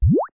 effect_bubble_2